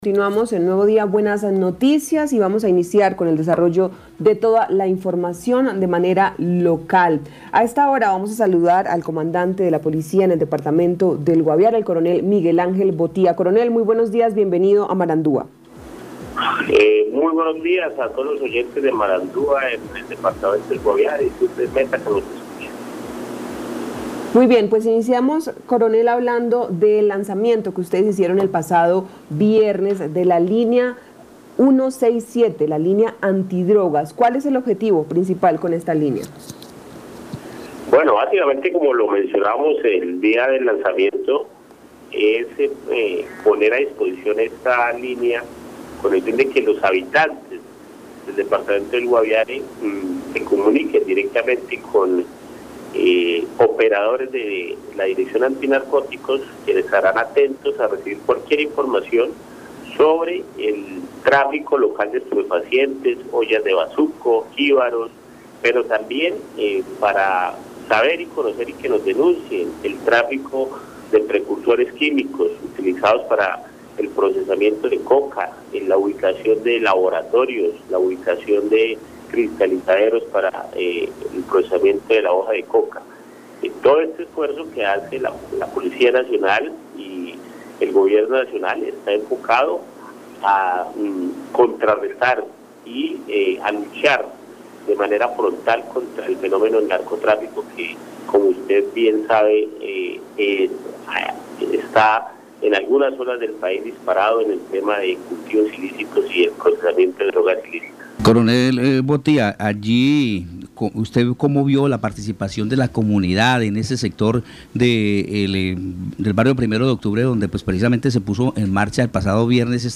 Esucche al Coronel Miguel Ángel Botía, comandante de Policía Guaviare.